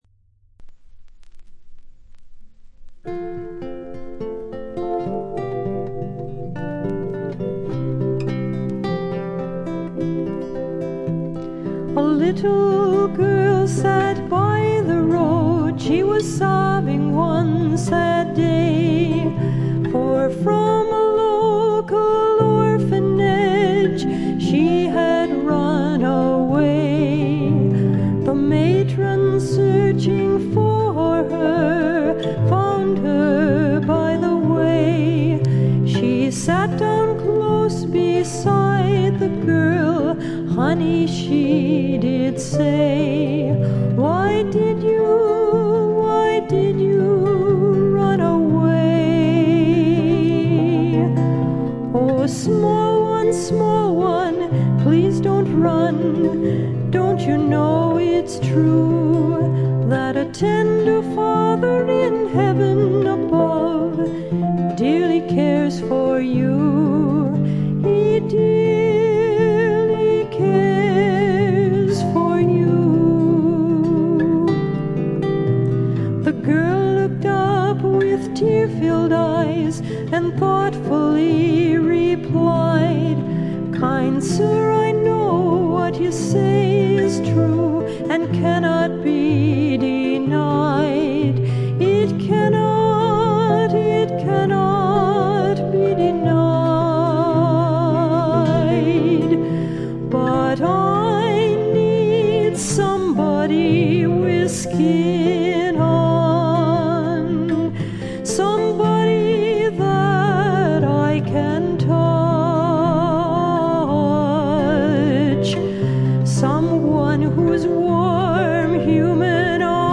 軽いチリプチ程度。
ミネアポリス産クリスチャン・ミュージック／女性シンガーソングライターの佳作。
この時点で一児の母親のようですが、純真無垢な歌声に癒やされます。
試聴曲は現品からの取り込み音源です。
12-String Acoustic Guitar, Classical Guitar, Electric Piano
Piano, Celesta [Celeste]
Electric Bass
Drums
Trumpet
Recorded At - Sound 80 Studios